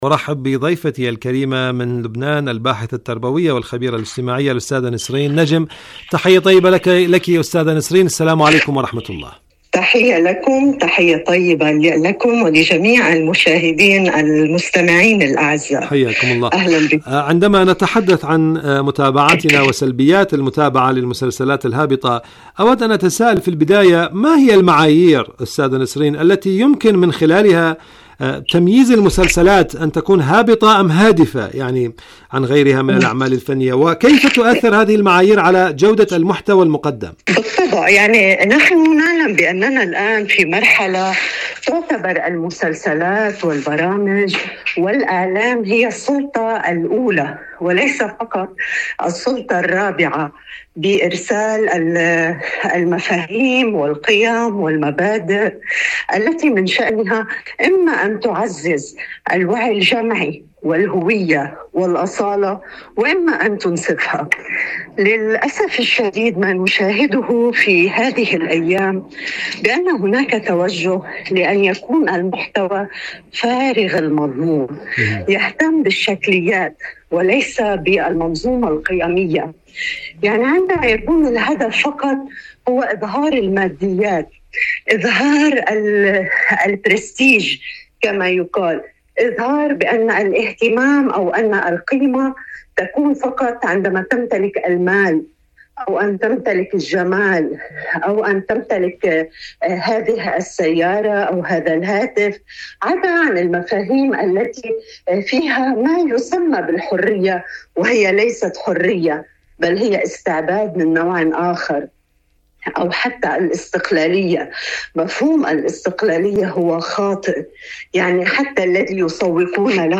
برنامج معكم على الهواء مقابلات إذاعية